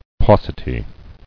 [pau·ci·ty]